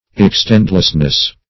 Search Result for " extendlessness" : The Collaborative International Dictionary of English v.0.48: Extendlessness \Ex*tend"less*ness\, n. Unlimited extension.